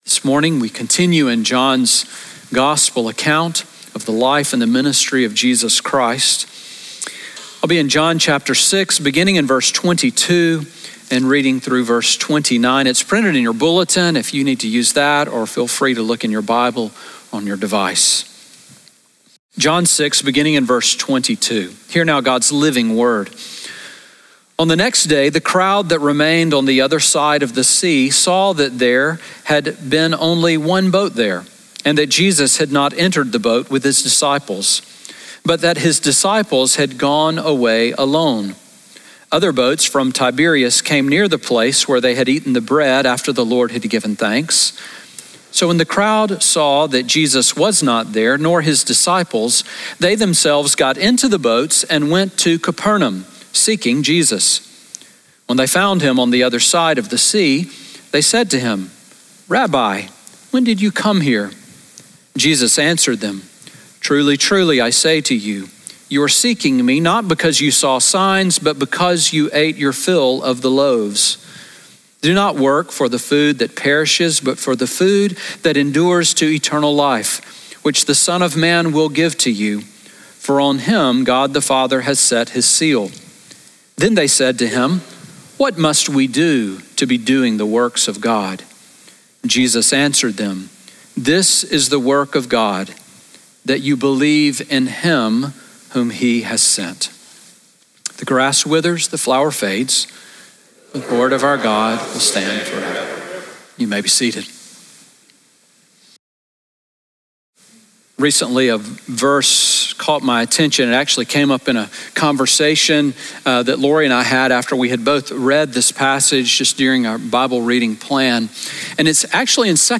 Sermon Audio from Sunday
Sermon on John 6:22-29 from September 7